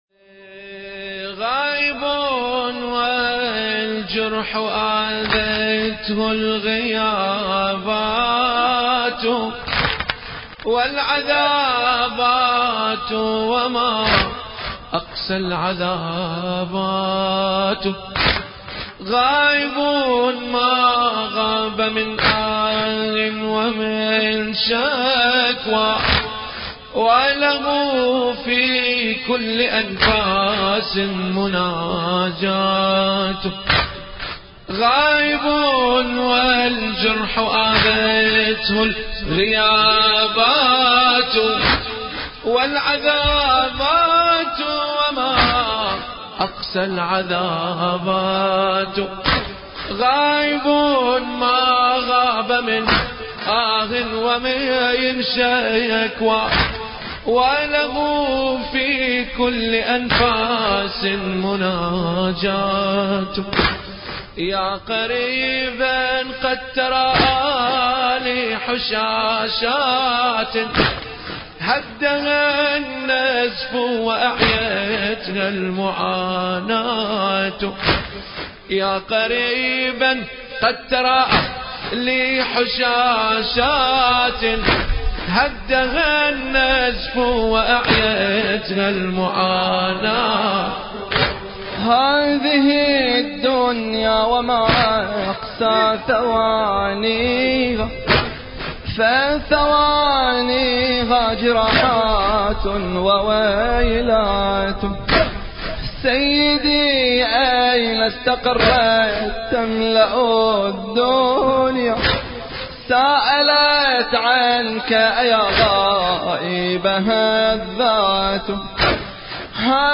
مراثي مشتركة
موكب عزاء مأتم بن خميس – السنابس – البحرين